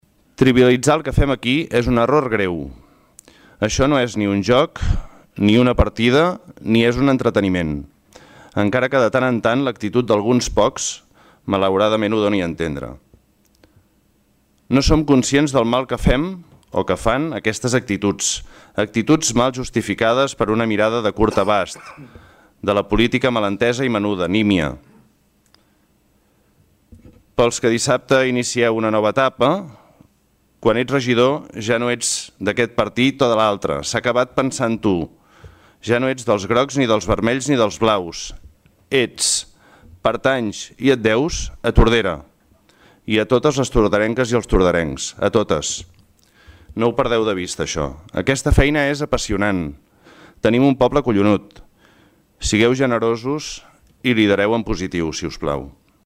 Tots els regidors que plegaven també van realitzar un darrer missatge d’acomiadament.